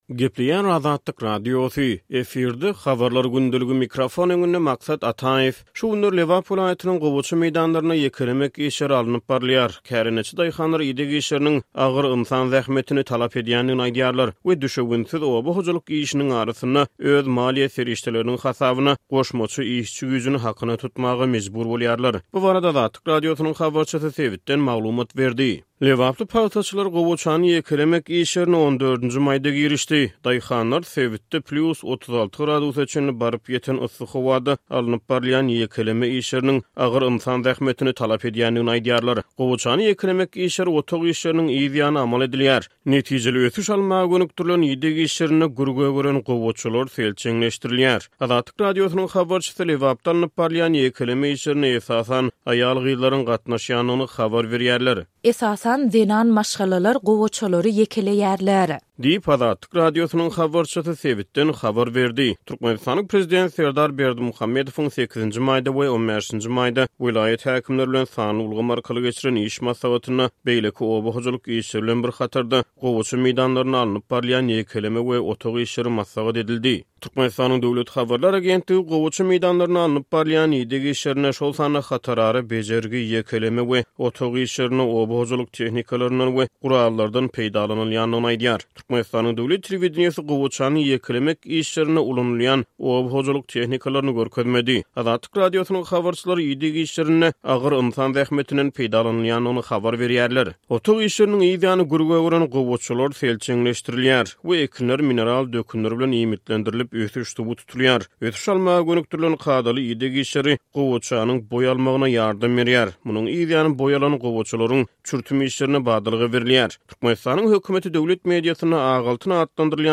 Şu günler Lebap welaýatynyň gowaça meýdanlarynda ýekelemek işleri alnyp barylýar. Kärendeçi daýhanlar ideg işleriniň agyr ynsan zähmetini talap edýändigini aýdýarlar we, düşewüntsiz oba hojalyk işiniň arasynda, öz maliýe serişdeleriniň hasabyna goşmaça işçi güýjüni hakyna tutmaga mejbur bolýarlar. Bu barada Azatlyk Radiosynyň habarçysy sebitden maglumat berdi.